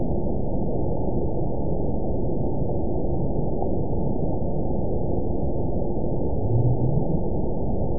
event 920614 date 04/01/24 time 01:26:24 GMT (1 year, 8 months ago) score 8.29 location TSS-AB02 detected by nrw target species NRW annotations +NRW Spectrogram: Frequency (kHz) vs. Time (s) audio not available .wav